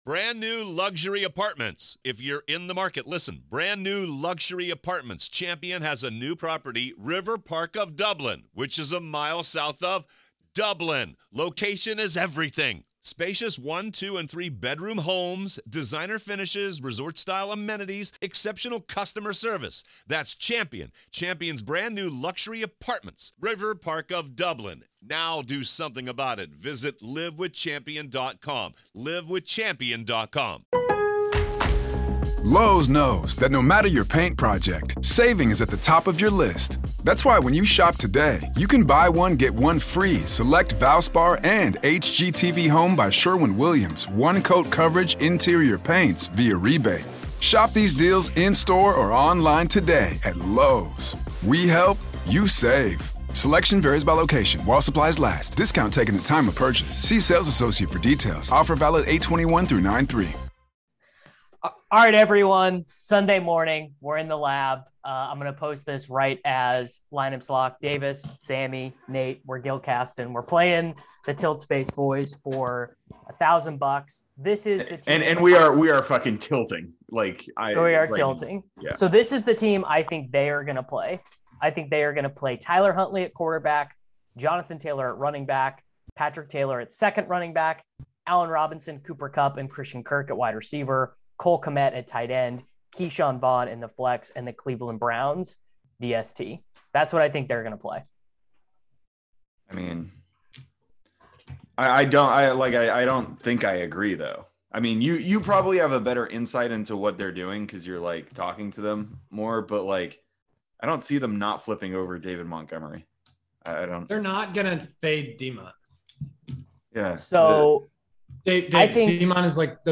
figure out what lineup to play against the Tilt Space boys live on air